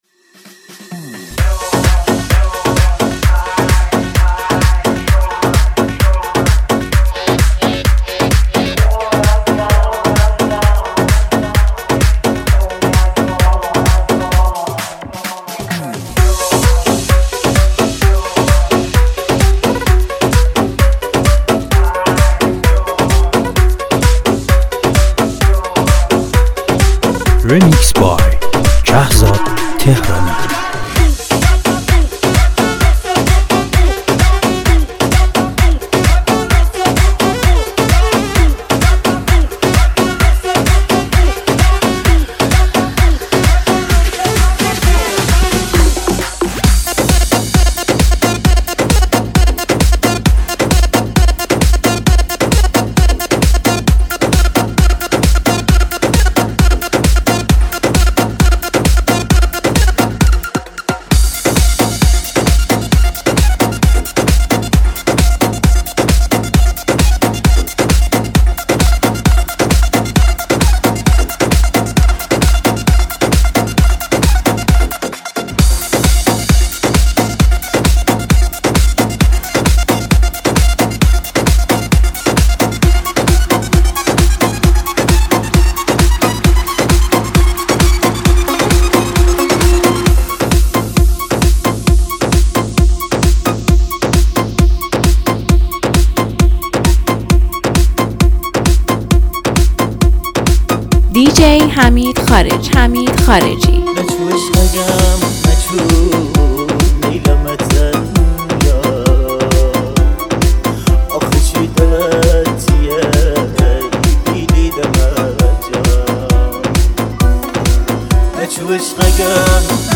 یک میکس پرانرژی و شنیدنی برای طرفداران موسیقی.